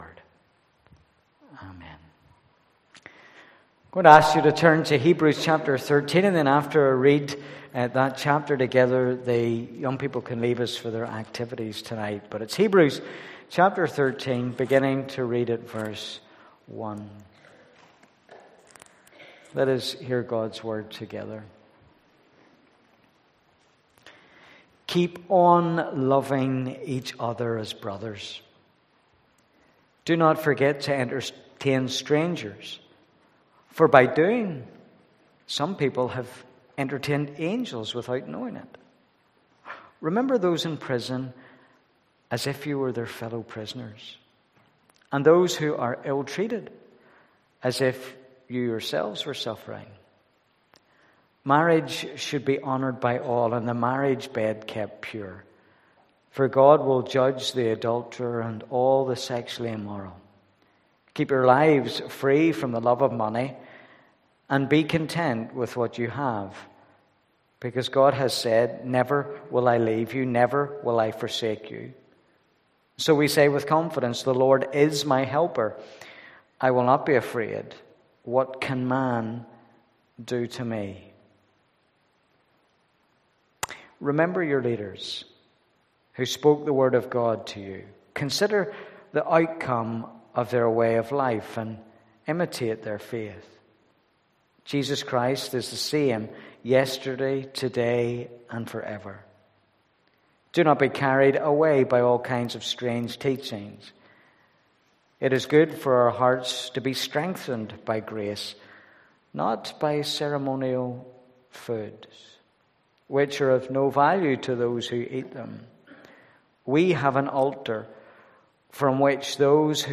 Service Type: pm